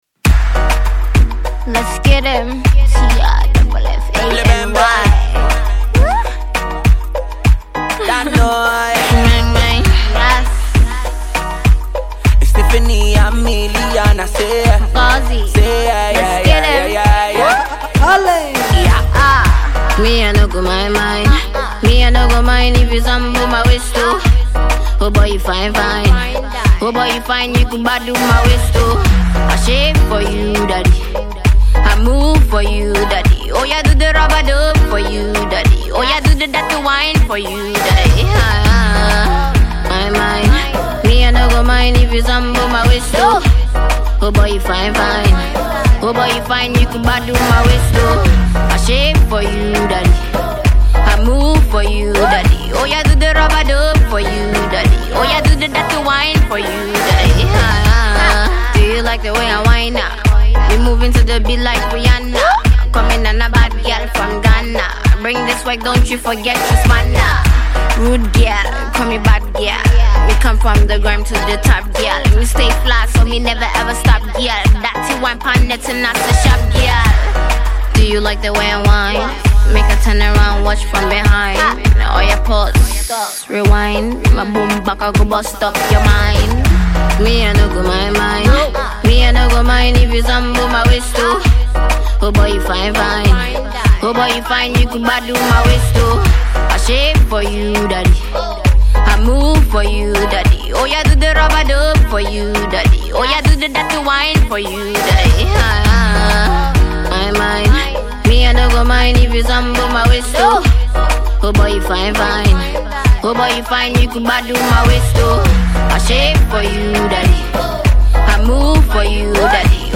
Ghanaian female singer and rapper
Nigerian singer